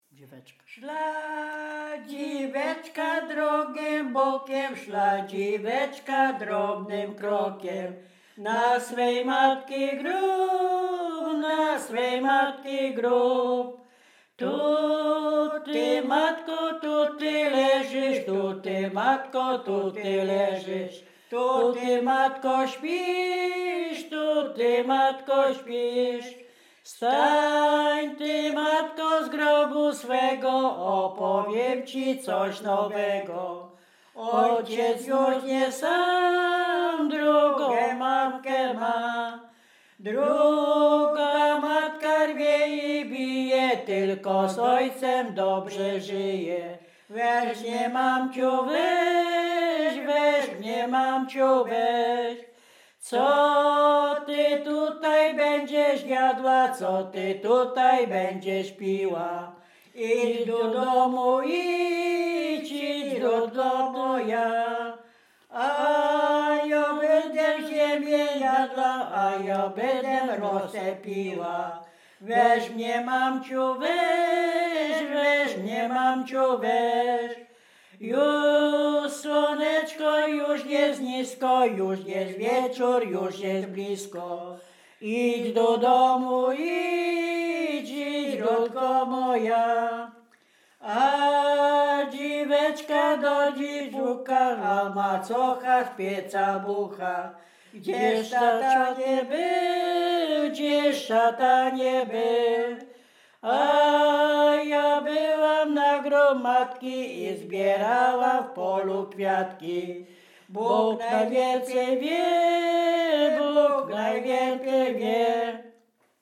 Bukowina Rumuńska
ballady dziadowskie sieroce